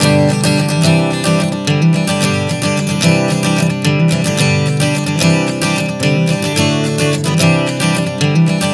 Prog 110 G-C-D-C.wav